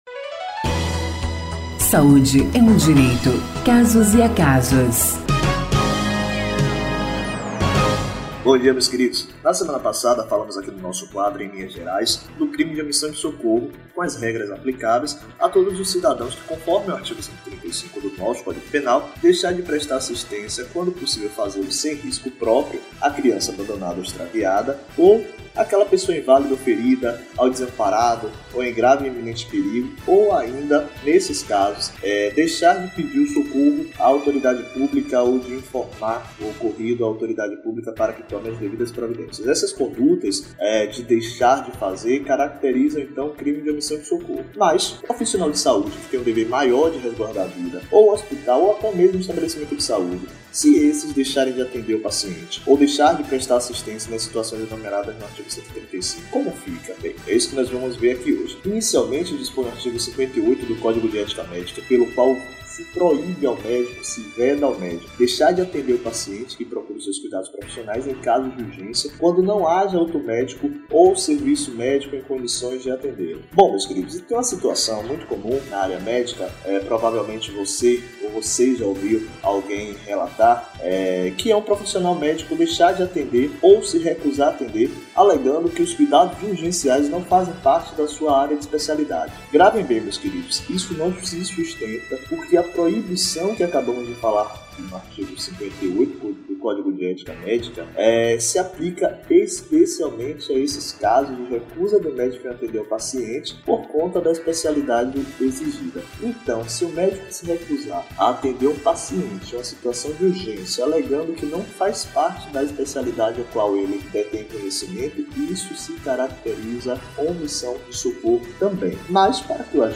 Ouça o comentário na íntegra e saiba as consequências para o profissional de saúde, ao deixar de prestar um socorro.